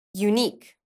“Unique” is pronounced /juːˈniːk/ and is divided into two syllables: “u-nique.”
Unique Pronunciation:
Unique-pronounced.mp3